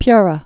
(pyrə, pyrä)